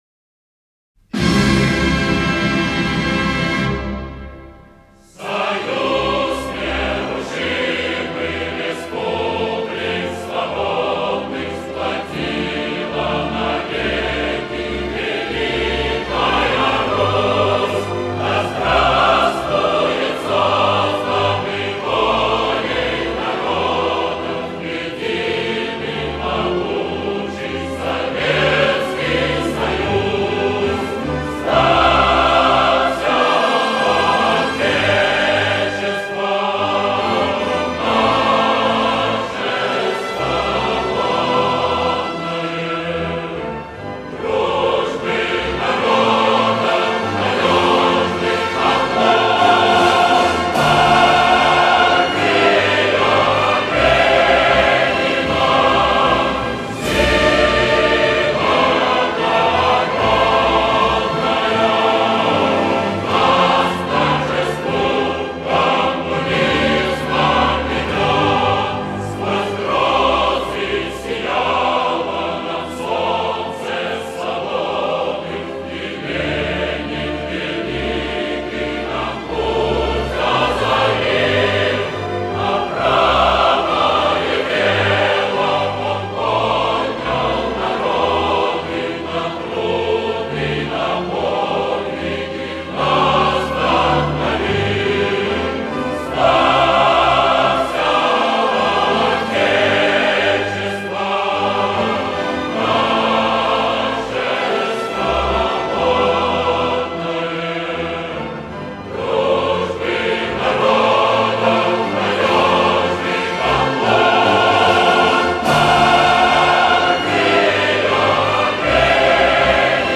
Государственный Гимн Союза Советских Социалистических Республик (СССР)  : в исполнении хора / музыка А.А. Александрова, слова С. Михалкова, Г. Эль-Регистана.